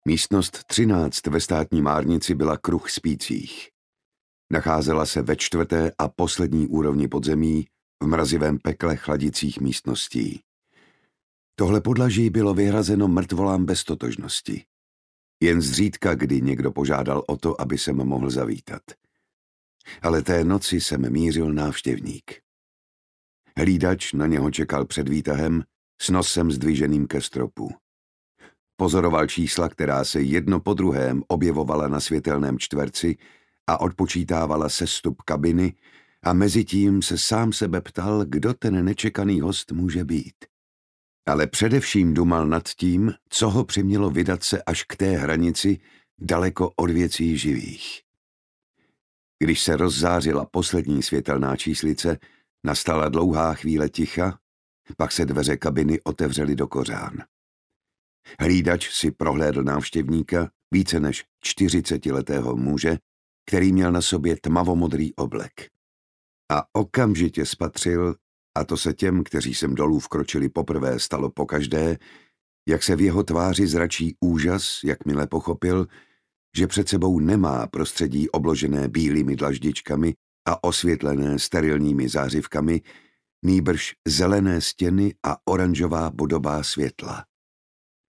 Hypotéza zla audiokniha
Ukázka z knihy
• InterpretJan Šťastný